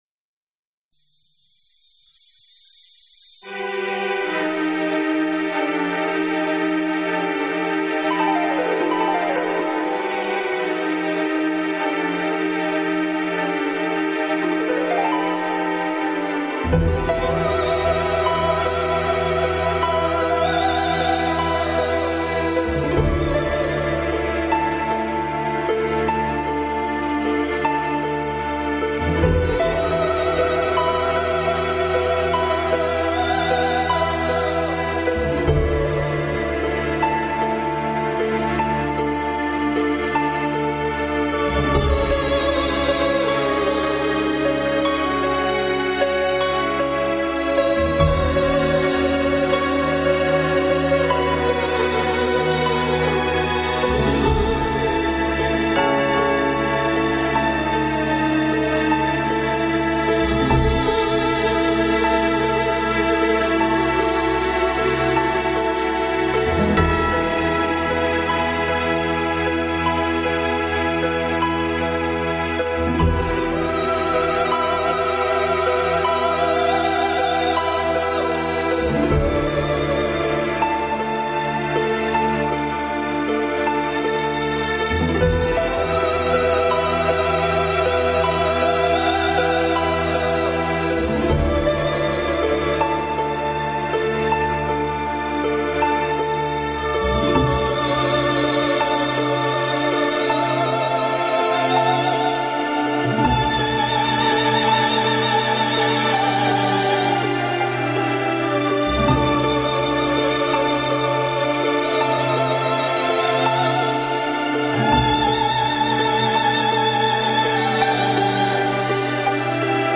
вокализ